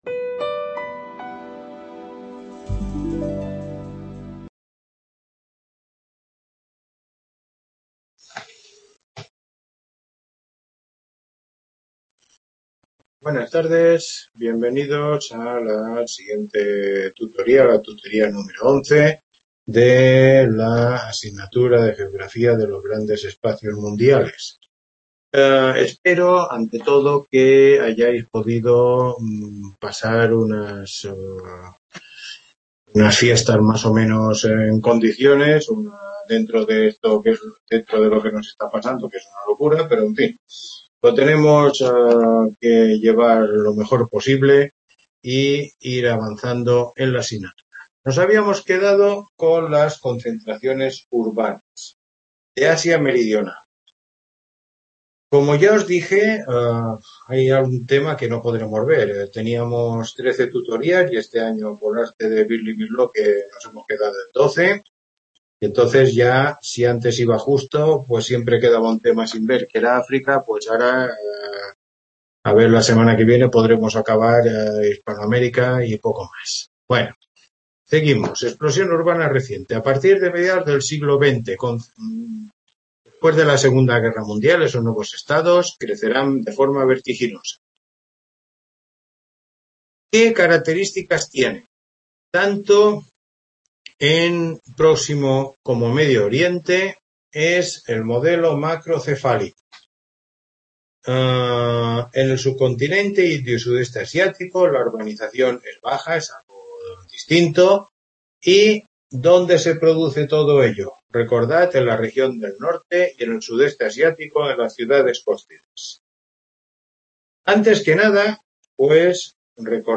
Tutoría 11